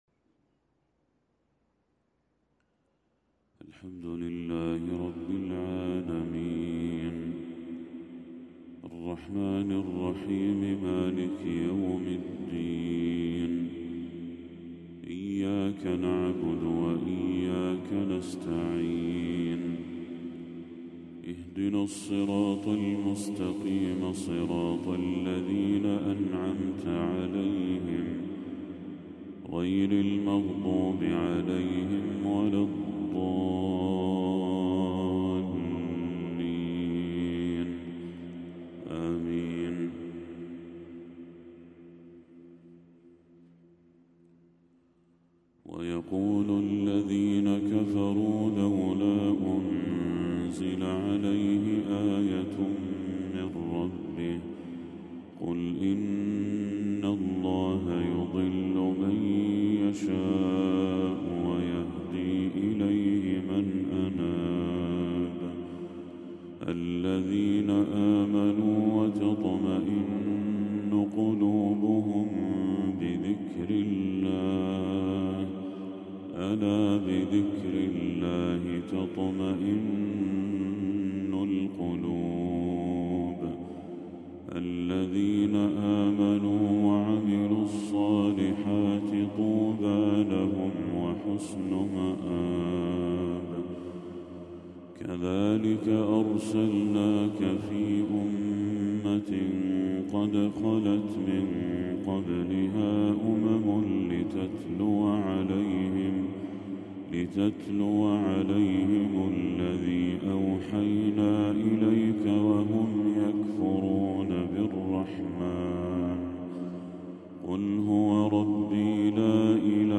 تلاوة لخواتيم سورة الرعد للشيخ بدر التركي | فجر 26 ربيع الأول 1446هـ > 1446هـ > تلاوات الشيخ بدر التركي > المزيد - تلاوات الحرمين